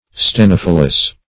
stenophyllous - definition of stenophyllous - synonyms, pronunciation, spelling from Free Dictionary
Search Result for " stenophyllous" : The Collaborative International Dictionary of English v.0.48: Stenophyllous \Ste*noph"yl*lous\, a. [Gr. steno`s narrow + fy`llon leaf.] (Bot.) Having narrow leaves.